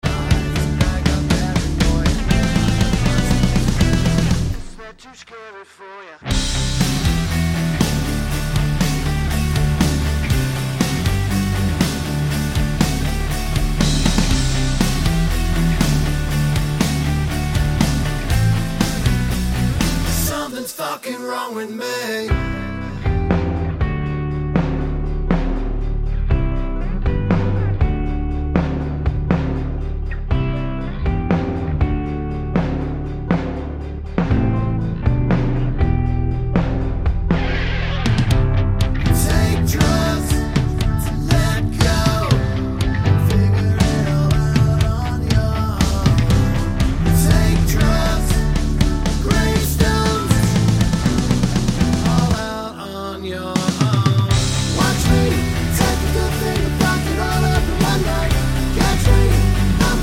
Explicit R'n'B / Hip Hop 2:50 Buy £1.50